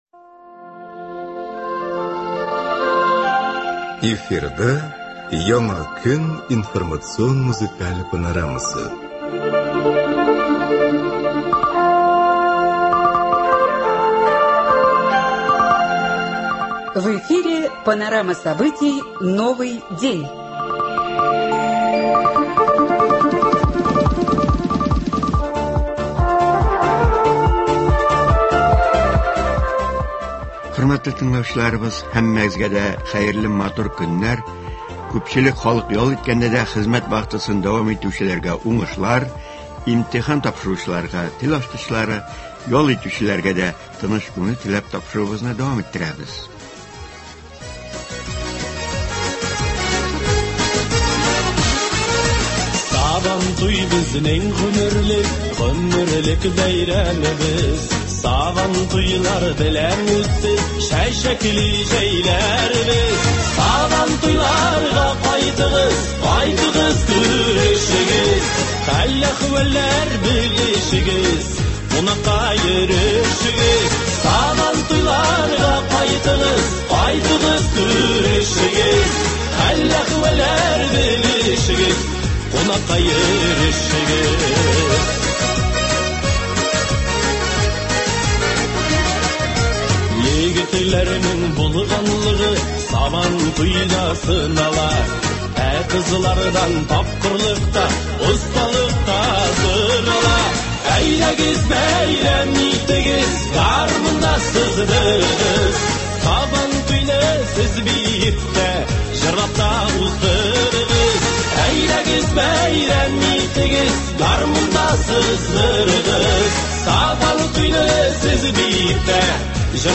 Теләче муниципаль районы җитәкчесе Нәҗип Хаҗипов белән әңгәмә шушы темага багышлана.